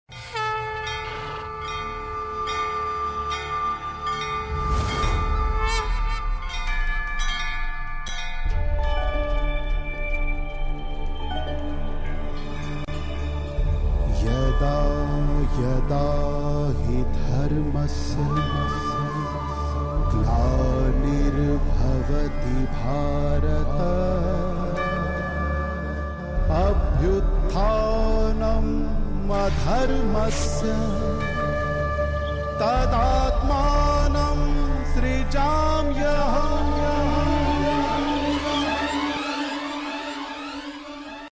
Tv Serials Full Songs